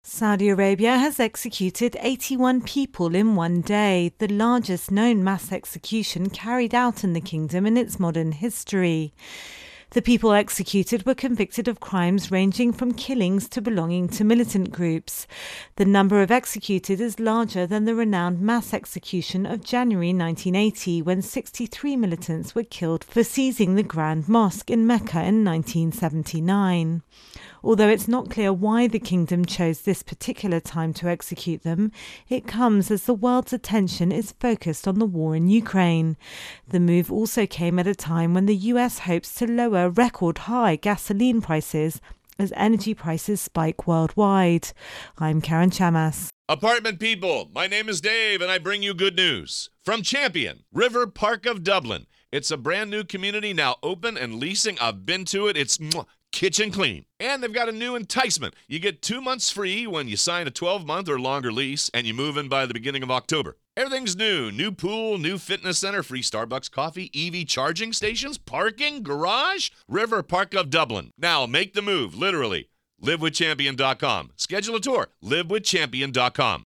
Intro and voicer on Saudi Arabia.